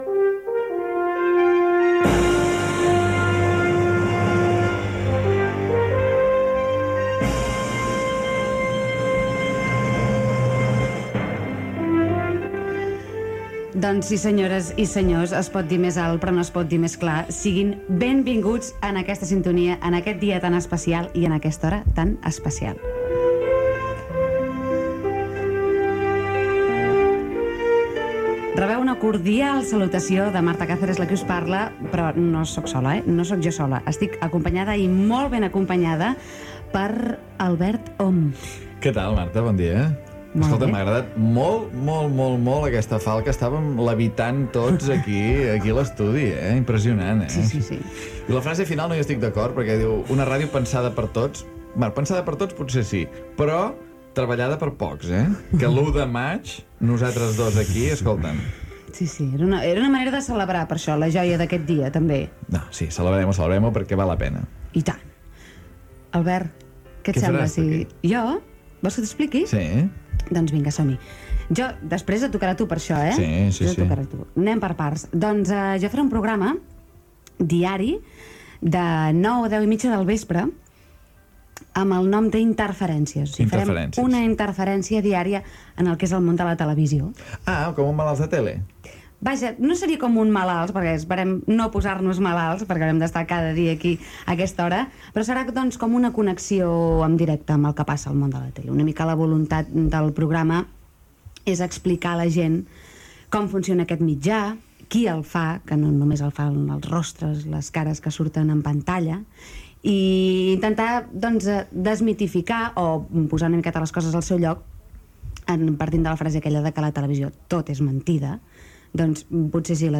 Tema musical i freqüències on es pot escoltar RAC 1
Emissió de presentació de RAC 1 el dia abans de la seva inauguració oficial.